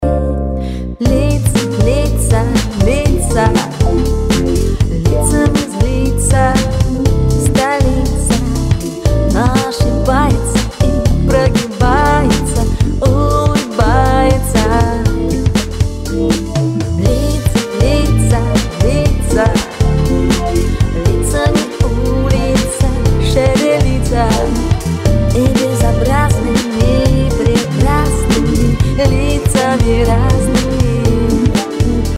Джаз-рок (342)